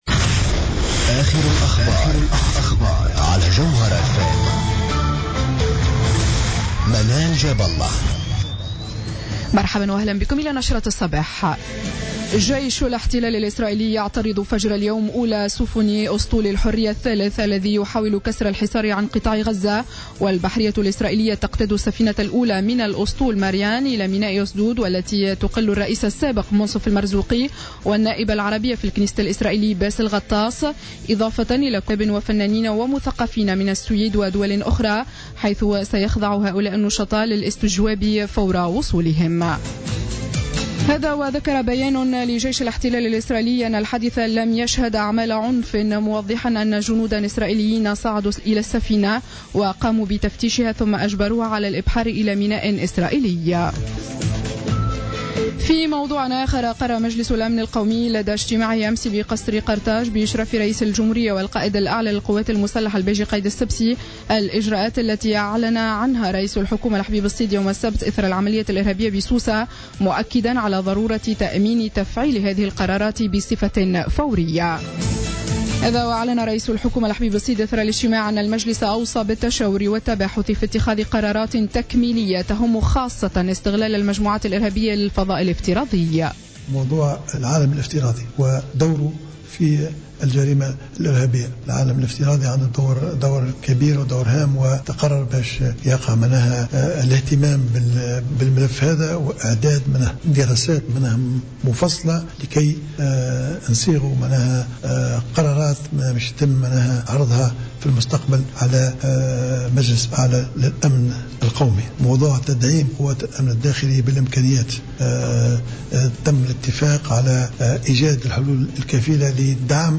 نشرة أخبار السابعة صباحا ليوم الاثنين 29 جوان 2015